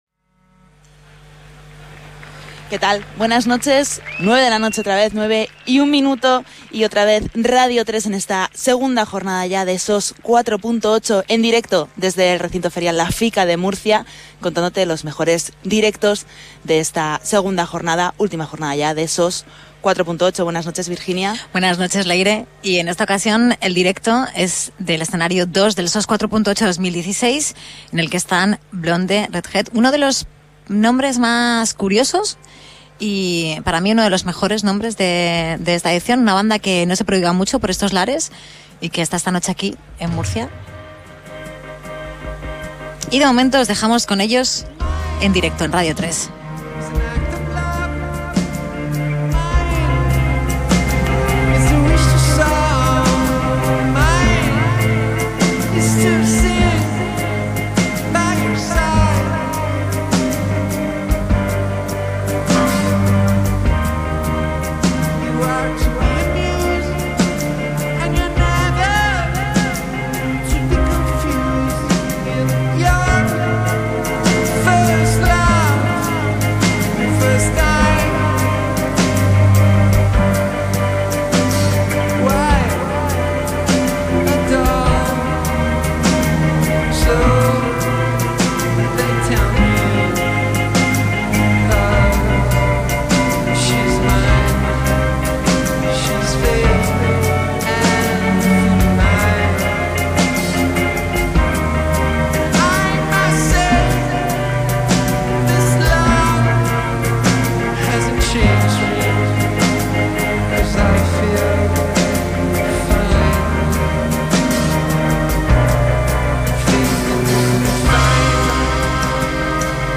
drums
lead guitar
rhythm guitarist/vocalist